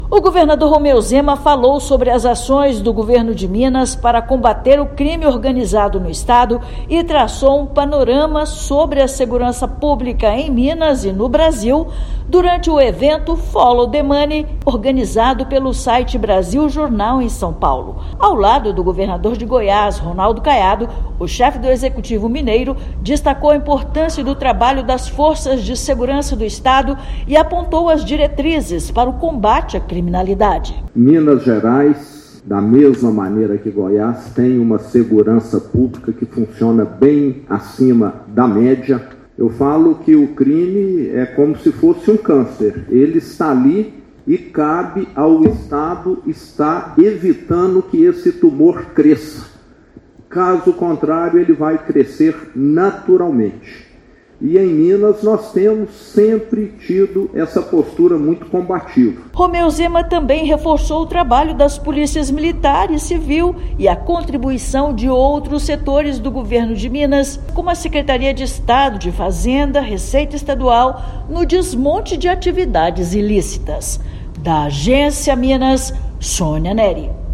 [RÁDIO] Governador de Minas aponta desafios e avanços da segurança pública durante evento em São Paulo
Chefe do Executivo mineiro aborda ações de combate ao crime organizado e de incentivo à formalização da economia. Ouça matéria de rádio.